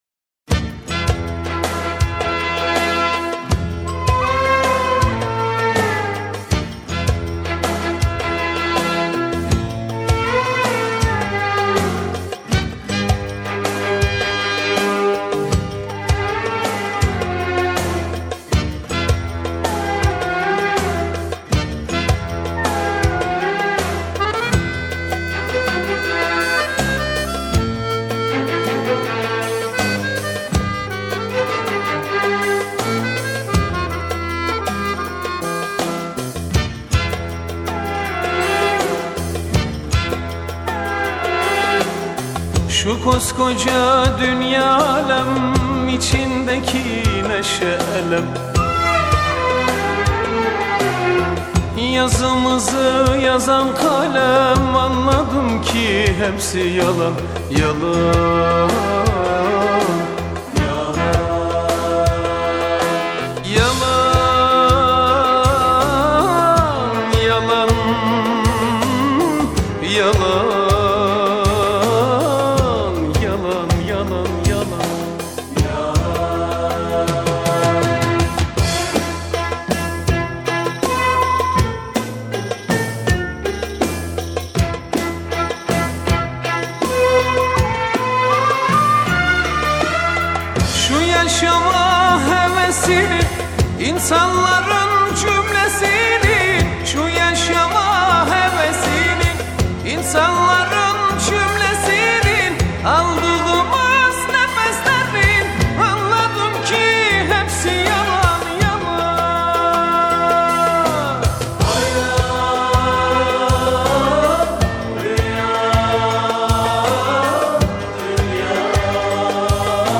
Arabesk, Turkish Pop